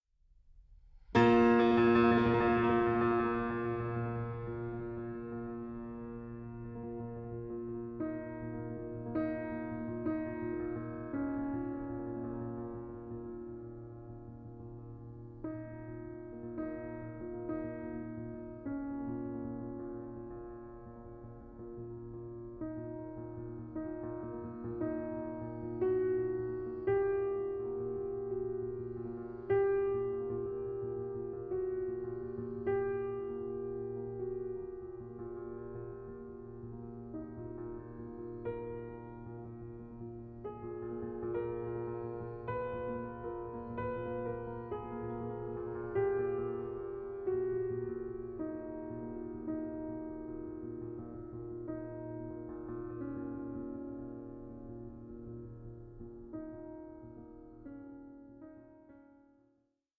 in F-Sharp Major: Fugue 4:27